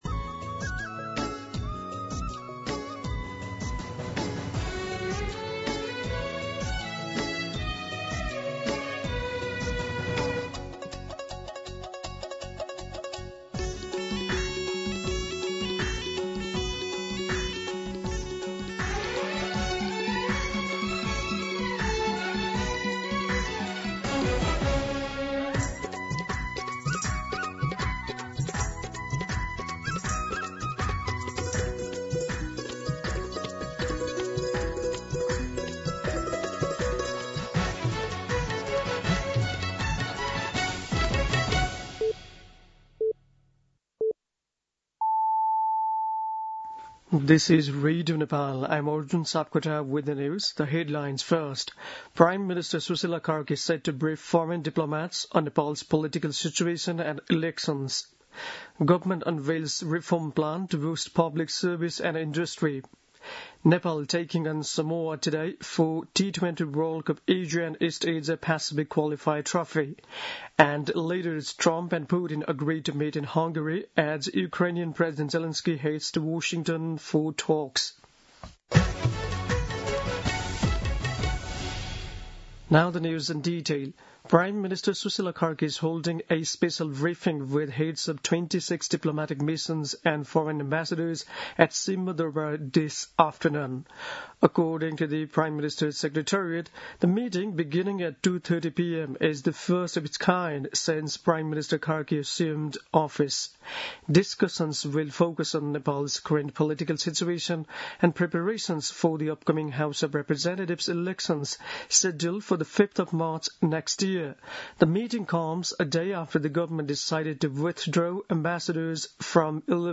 दिउँसो २ बजेको अङ्ग्रेजी समाचार : ३१ असोज , २०८२
2-pm-English-News-5.mp3